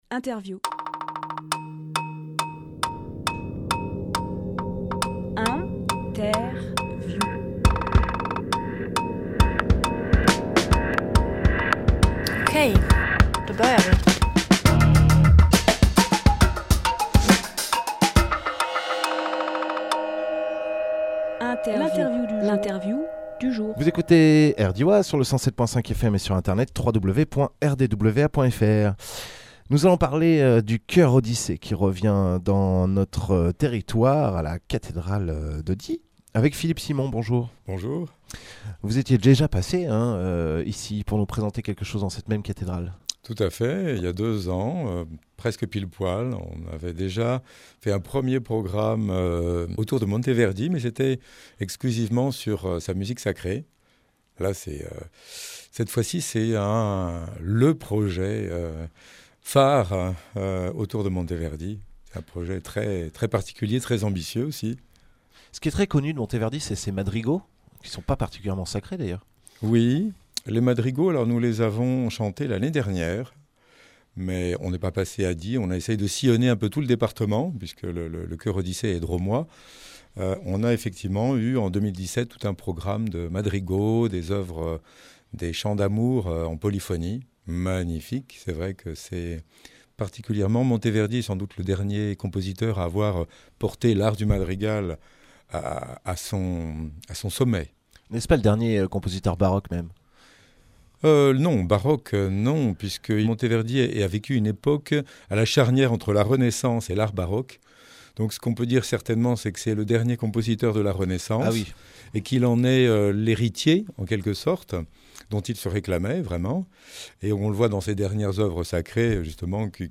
Emission - Interview “Orfeo Imaginaire” par le Chœur Odyssée Publié le 20 septembre 2018 Partager sur…
Lieu : Studio RDWA